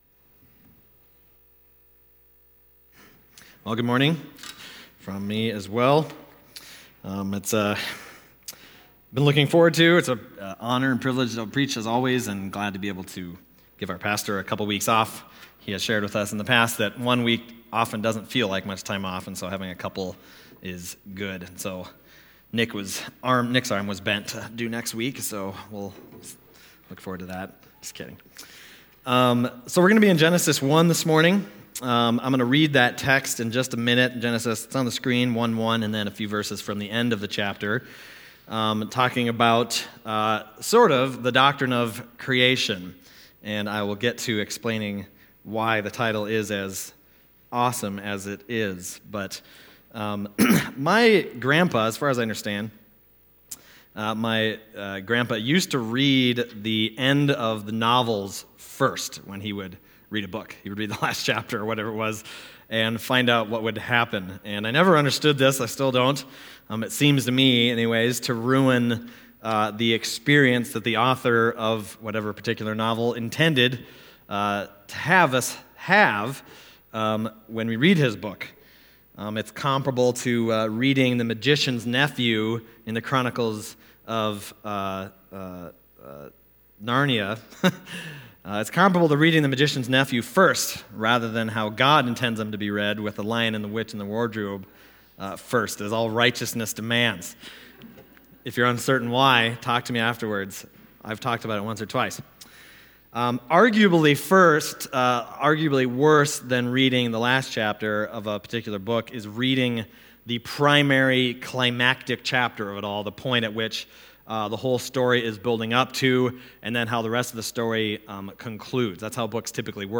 Passage: Genesis 1:1, Genesis 1:26-31 Service Type: Sunday Morning